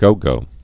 (gōgō)